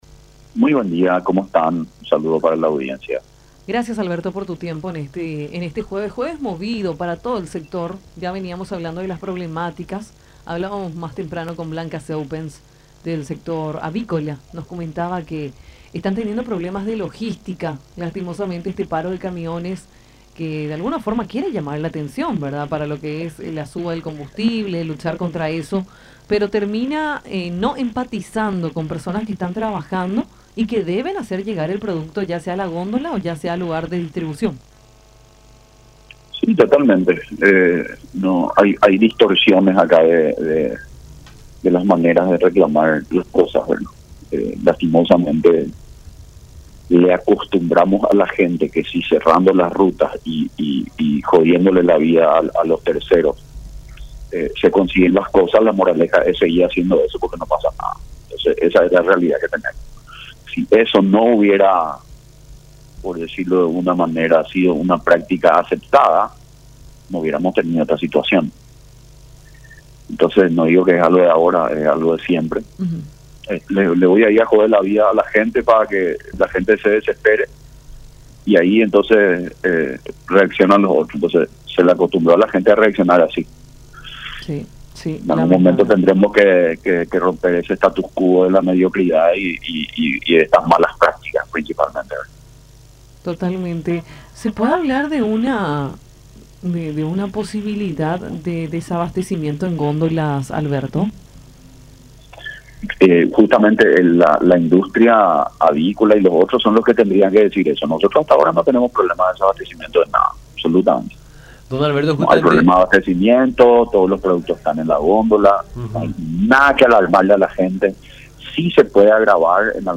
en diálogo con Nuestra Mañana por La Unión.